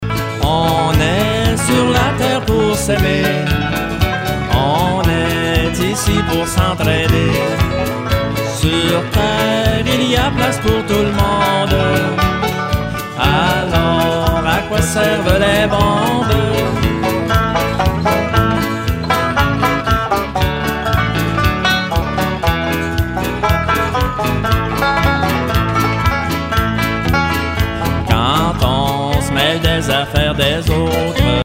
Enregistrement au studio